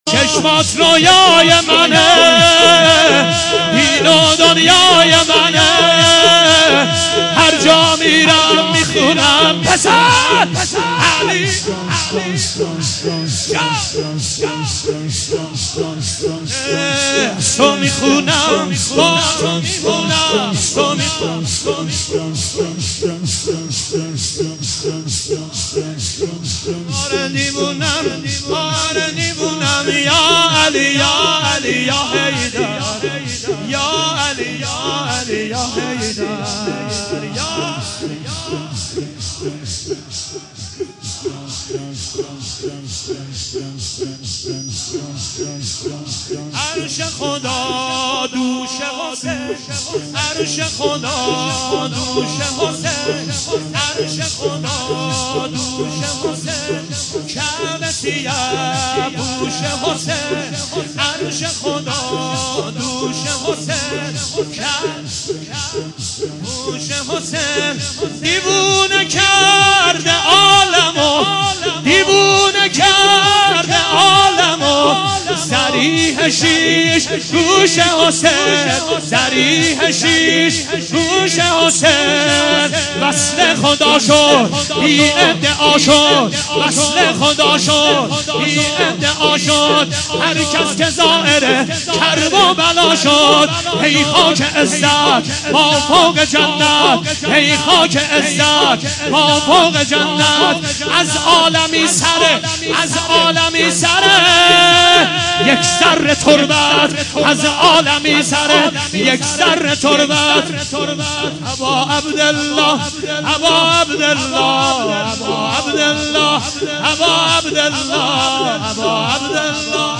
هیئت غریب مدینه مازندران (امیرکلا) شب دوم محرم۹۷